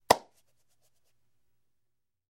Звуки рукопожатия
Звук поздравляющихся мужчин